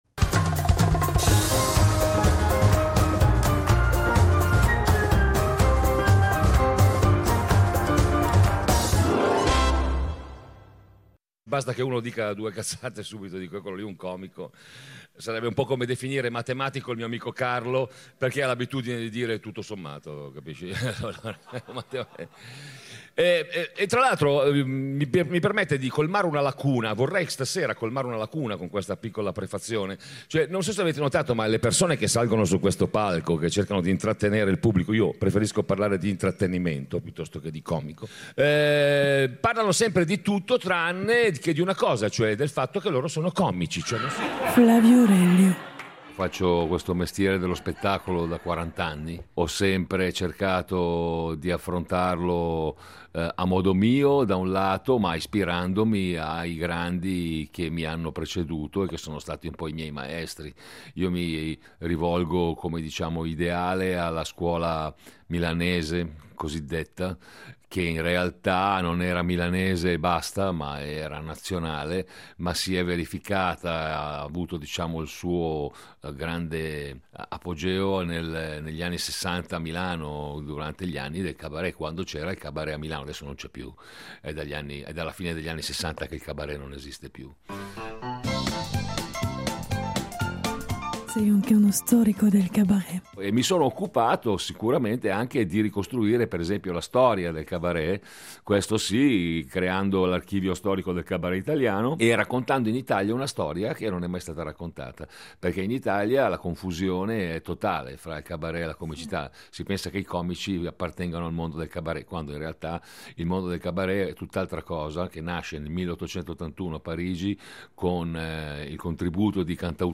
Il Dossier si apre lunedì con un reportage dallo Zelig